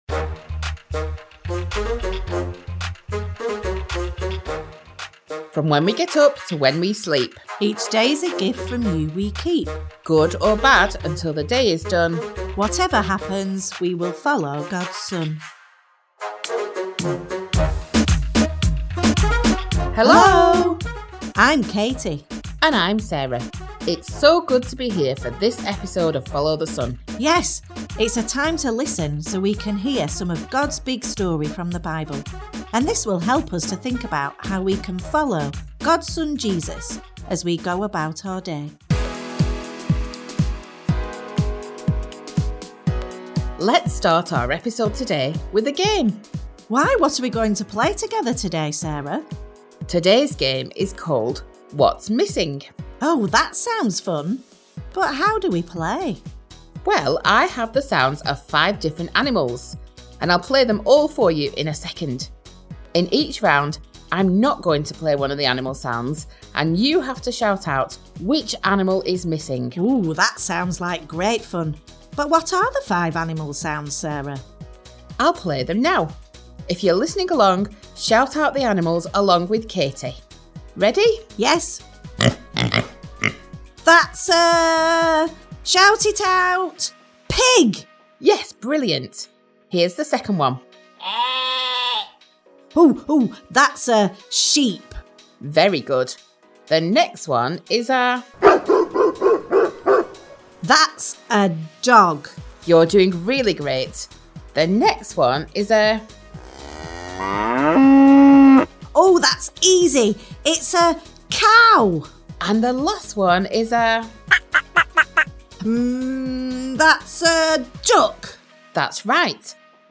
Morning and Bedtime Bible Stories for Children